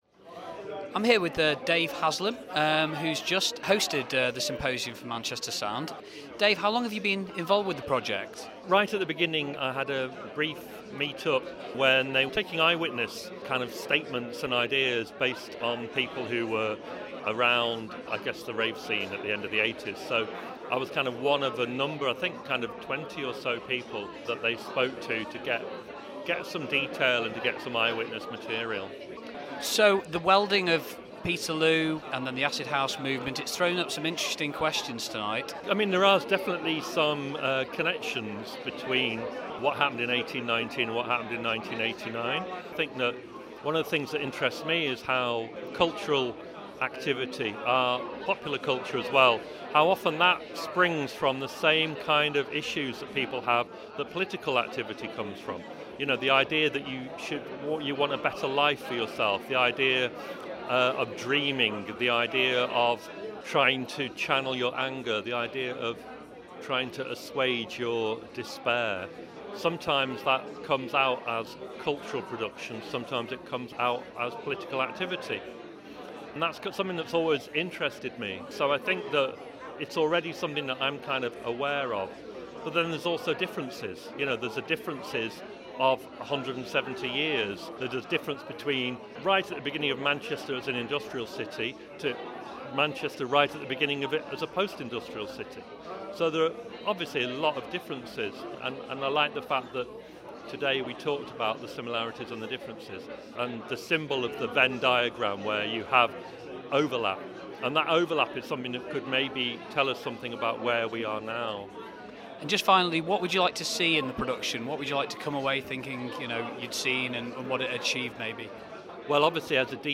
We caught up with DJ and writer Dave Haslam at last week's press launch of Manchester Sound: The Massacre. Dave kindly led our panel discussion and shared a few of his thoughts on the upcoming production.